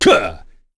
Nicx-Vox_Damage_01.wav